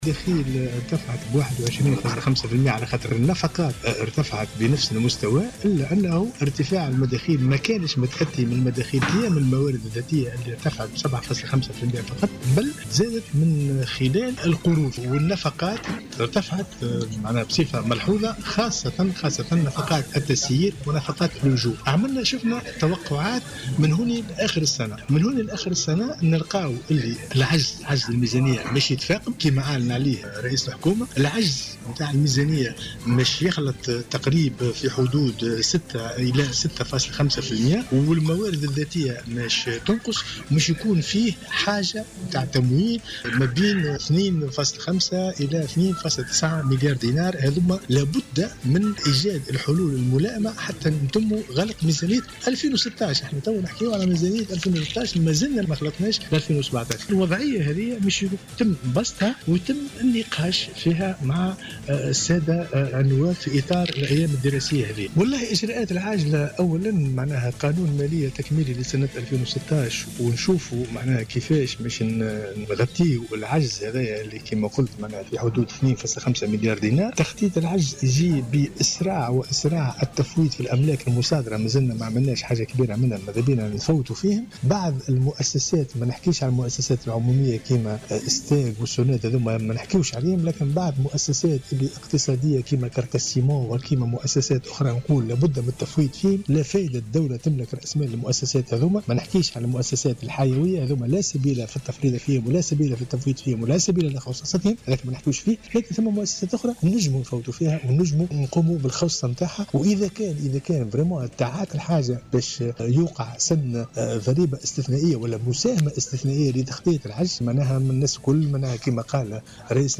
وقال في تصريحات صحفية على هامش تظاهرة برلمانية بالحمامات، إن هذا العجز يقتضي تمويلا اضافيا بنحو 2.9 مليارات دينار.